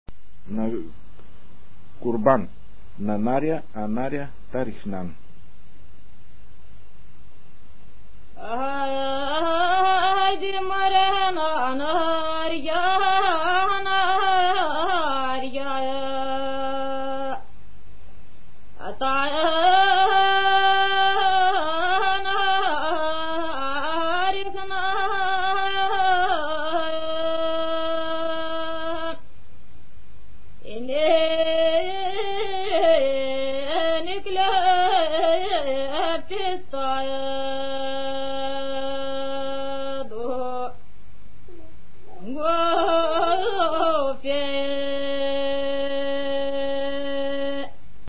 музикална класификация Песен
размер Безмензурна
фактура Едногласна
начин на изпълнение Солово изпълнение на песен
функционална класификация Ритуални (по повод)
етнос Каракачанска
фолклорна област Югоизточна България (Източна Тракия с Подбалкана и Средна гора)
начин на записване Магнетофонна лента